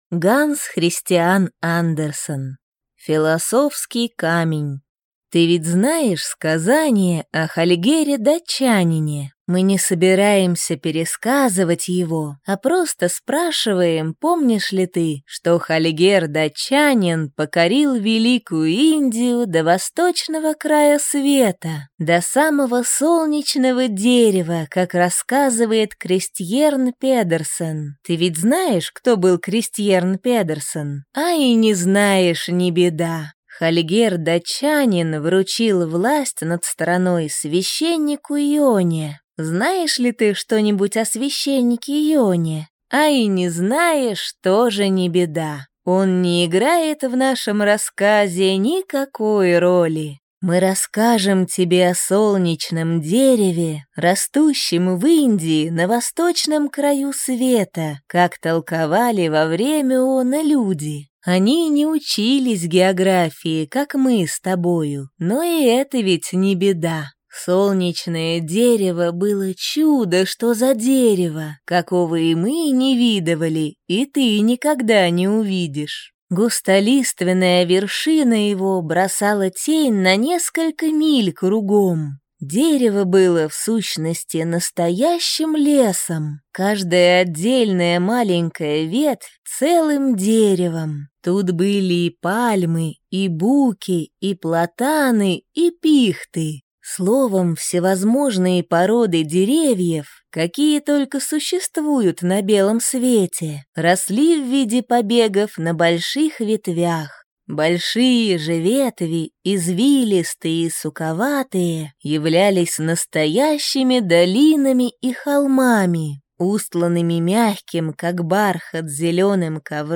Аудиокнига Философский камень | Библиотека аудиокниг